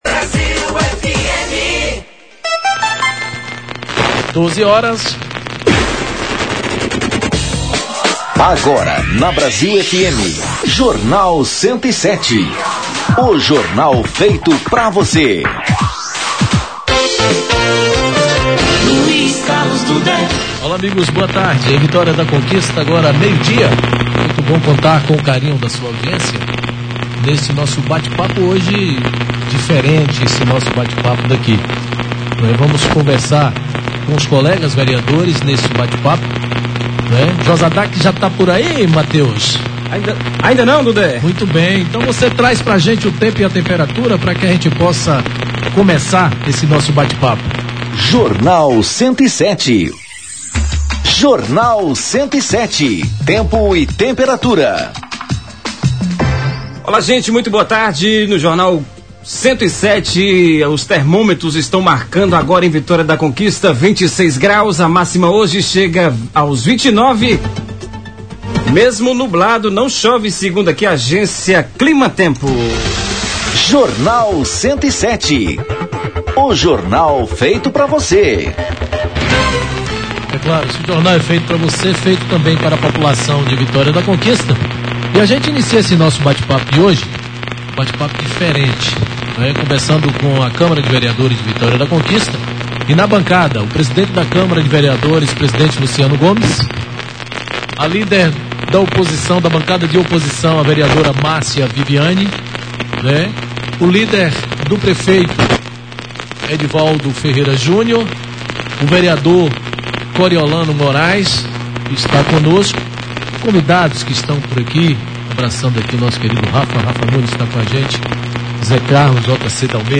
Em entrevista ao Jornal 107, programa da Rádio Brasil, desta quarta-feira (16), gravada direto do Plenário Vereadora Carmem Lúcia, o presidente da Câmara Municipal de Vitória da Conquista, Luciano Gomes Lisboa, do Partido Liberal, destacou a importância das matérias e também pelos acordos firmados entre as bancadas de tirar os Projetos do Regime de Urgência, como sugeriu o prefeito, para ampliar os debates.